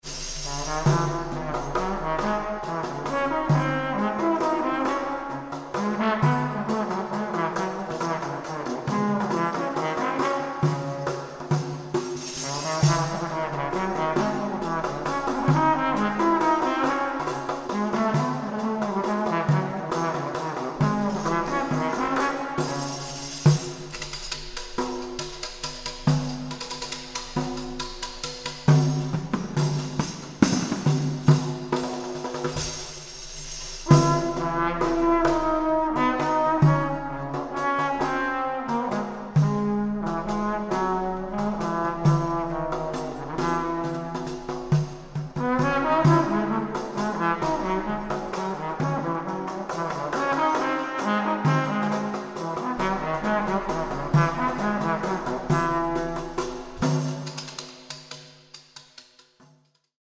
Historical Drums
Recording: Gut Hohen Luckow, 2024